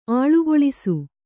♪ āḷugoḷisu